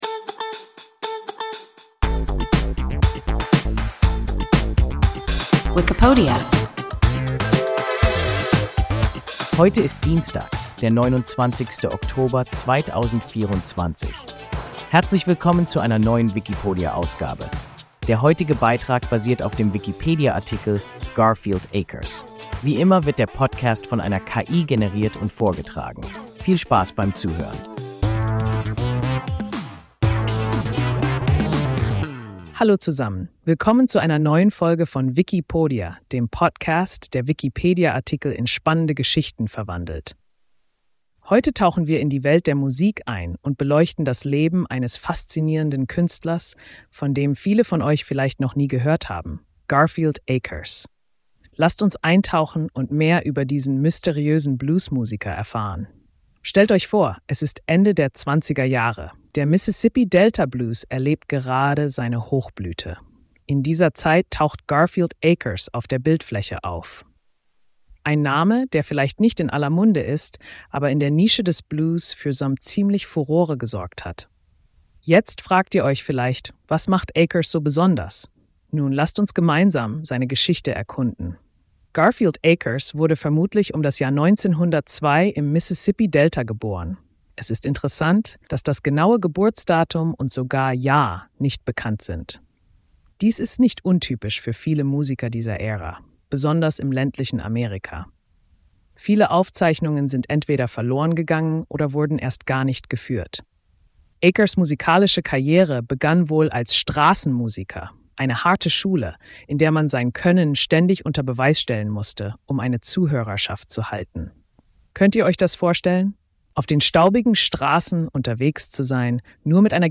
Garfield Akers – WIKIPODIA – ein KI Podcast